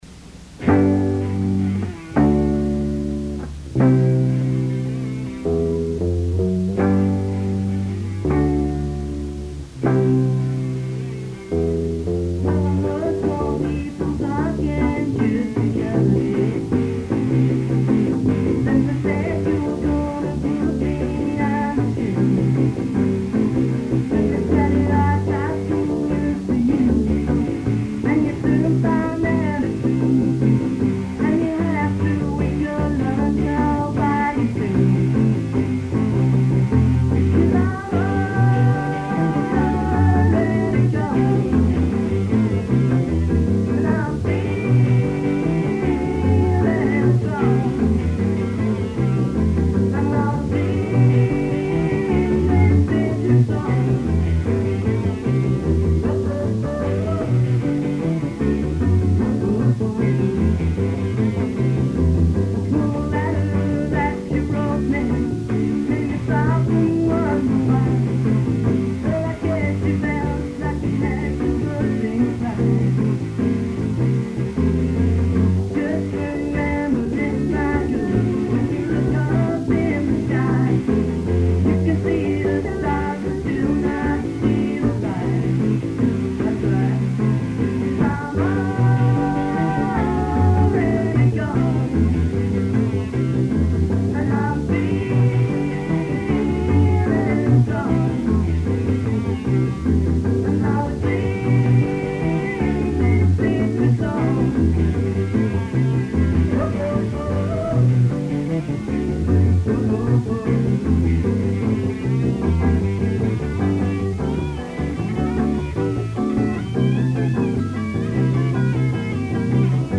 Lead Vocals and Guitar
Bass
Lead Vocals
Drums
Lead Guitar
Here are a few Orpheus covers recorded during a practice on Sunday 14th of June, 1976!: